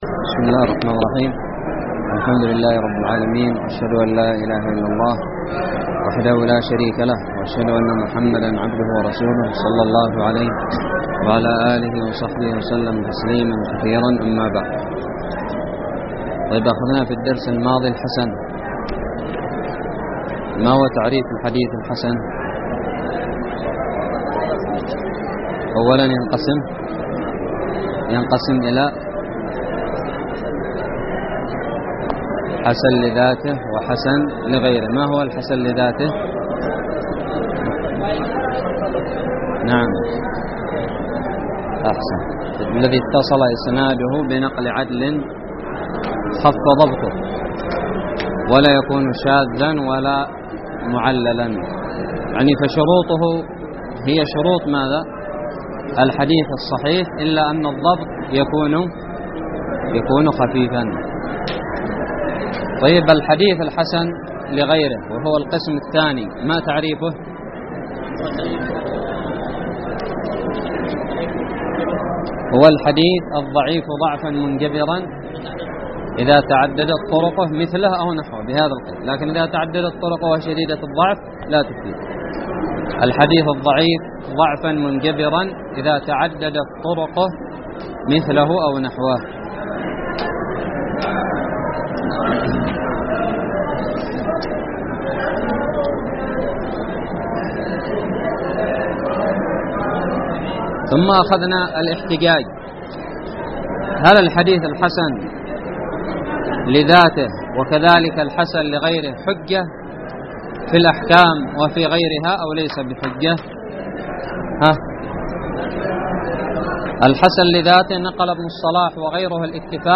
الدرس الثالث عشر من شرح كتاب نزهة النظر
ألقيت بدار الحديث السلفية للعلوم الشرعية بالضالع